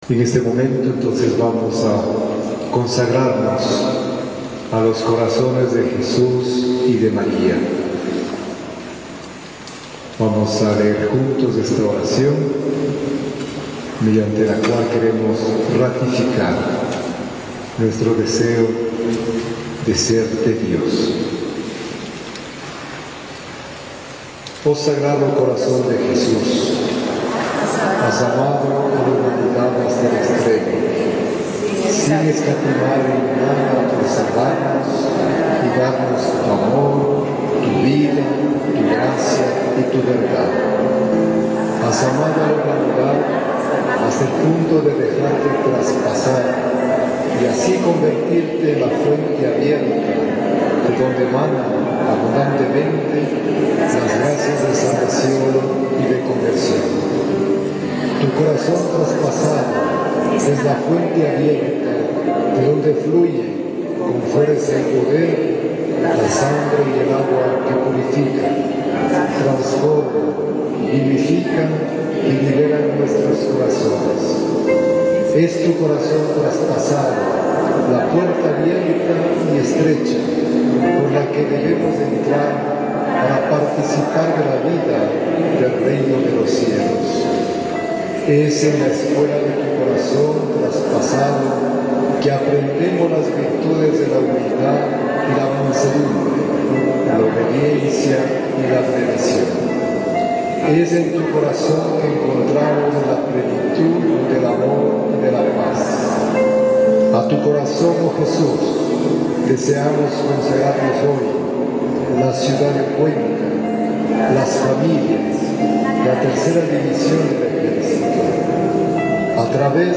On Friday, November 12, 2010, in the Parish of St. Blais, during the celebration of Holy Mass the Archbishop of Cuenca, Luis Gerardo Cabrera, ofm, consecrated the City of Cuenca, the families, the wombs of mothers, and the third division of the Army- whose General was present- to the Hearts of Jesus and Mary.